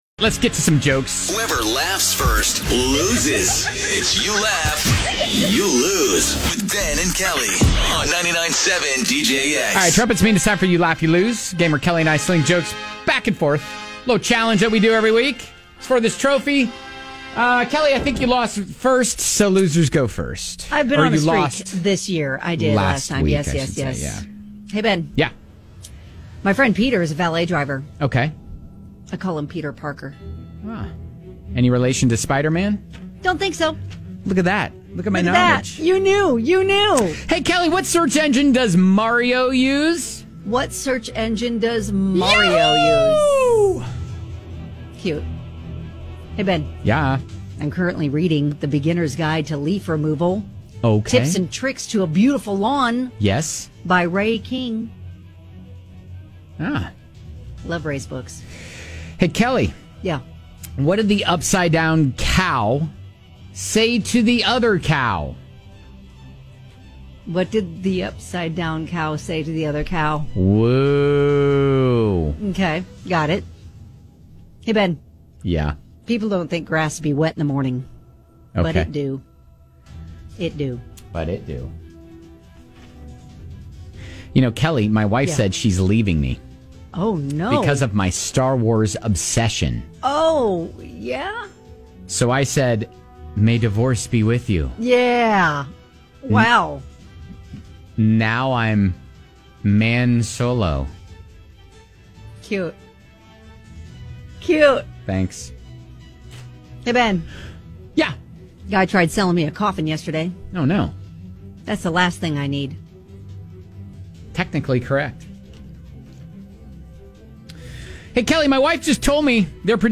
toss jokes back and forth until someone laughs